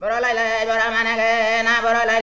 FOREIGN.wav